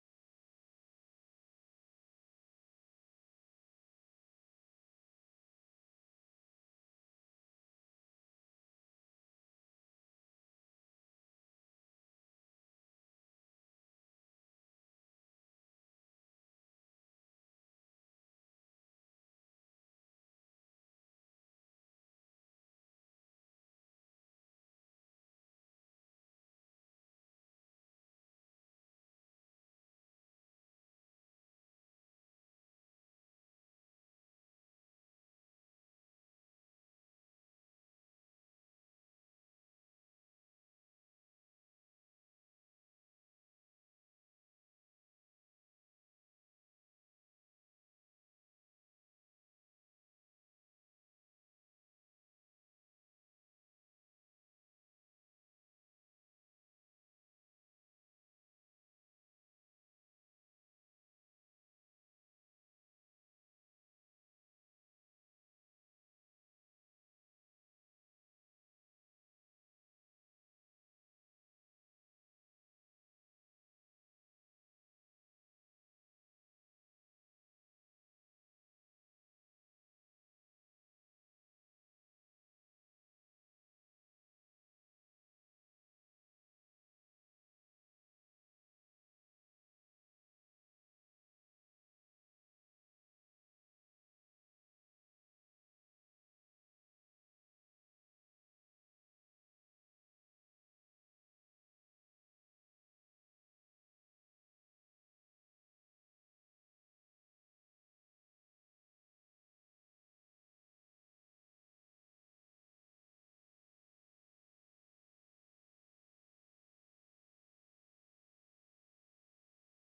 Teil 1 der Predigtreihe zum Jahresmotto 2026